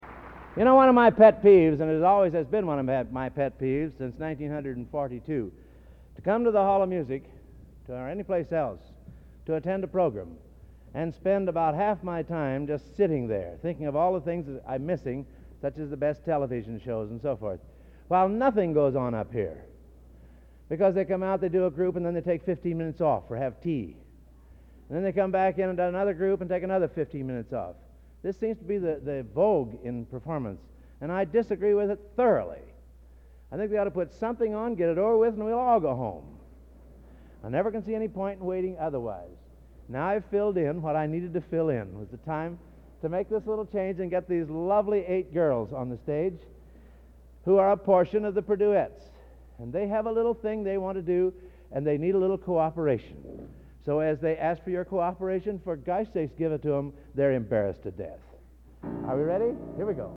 Collection: Gala Concert, 1961
Genre: | Type: Director intros, emceeing